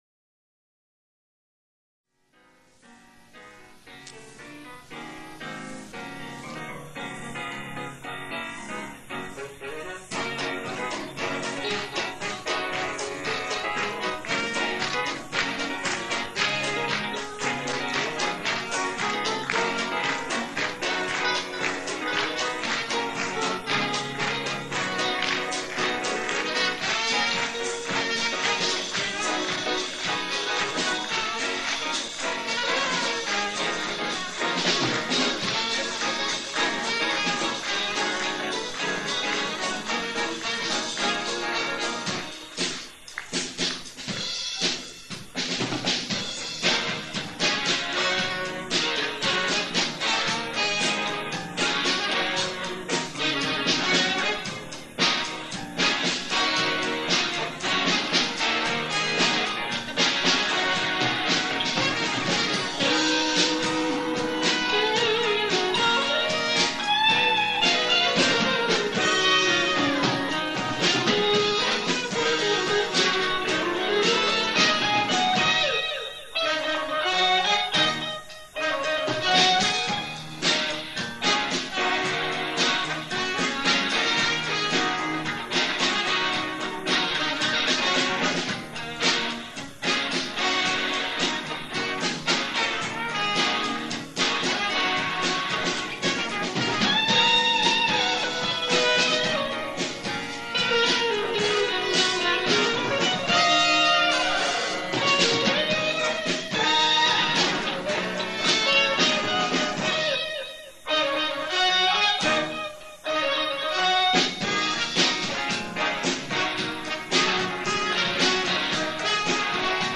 I digitized all the recordings from scratchy and warbly cassette tapes that sat in a box for decades.
Entr’acte II (from live show)